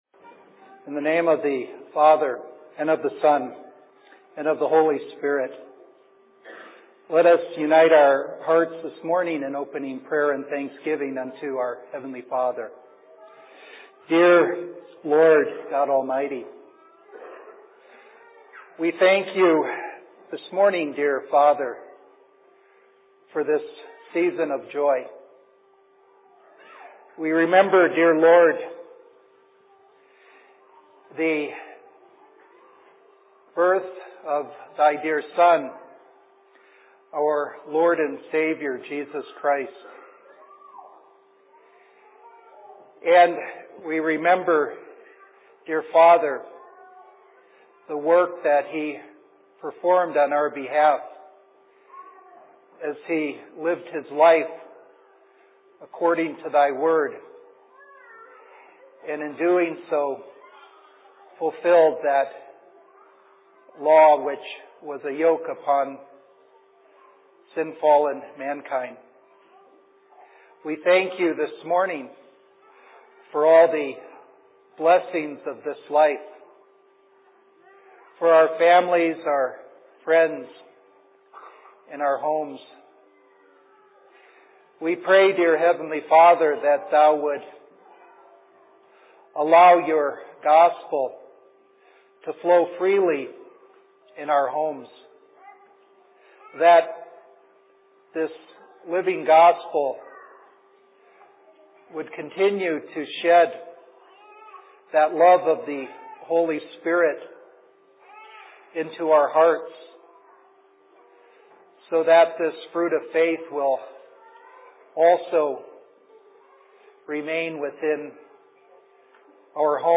Sermon in Phoenix 26.12.2010